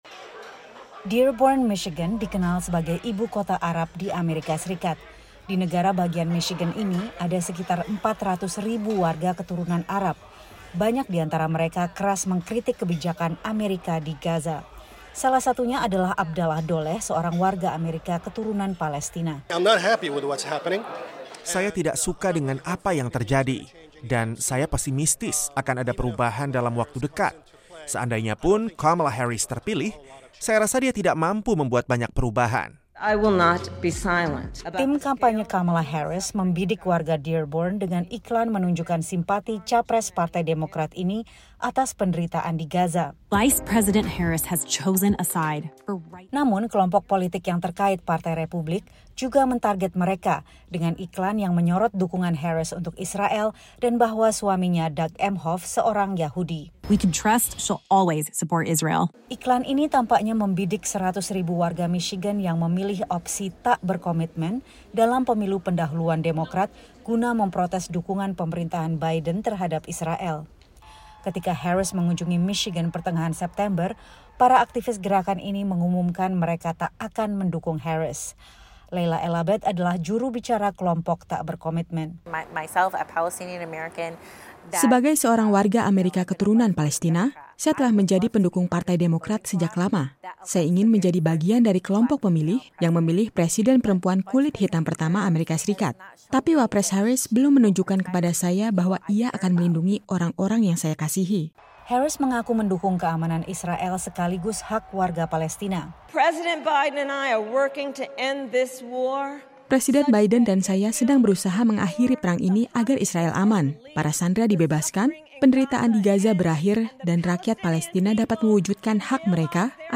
melaporkan dari Michigan, negara bagian dengan persentase penduduk keturunan Arab tertinggi di Amerika.